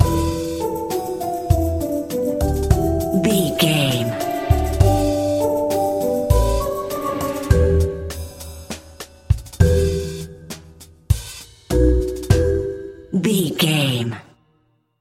Aeolian/Minor
strings
bass guitar
acoustic guitar
flute
percussion
circus
goofy
comical
cheerful
perky
Light hearted
quirky